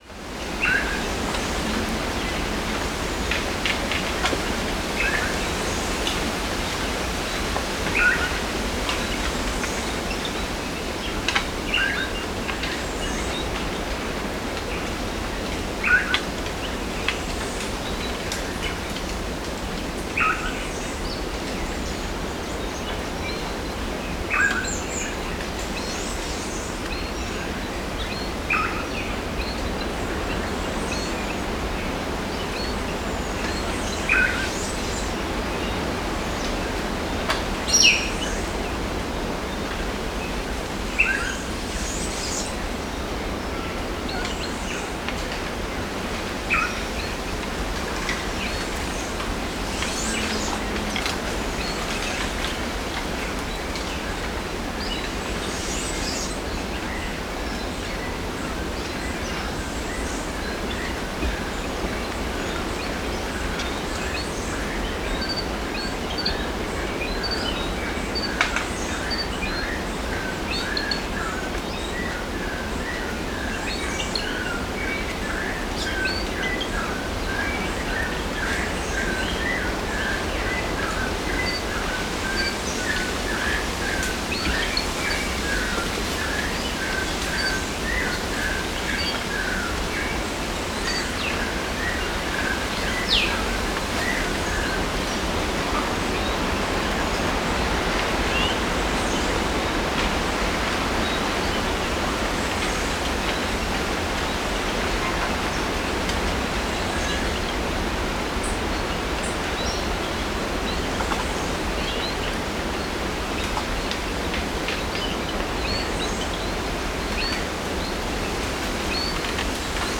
Bamboo_forest.L.wav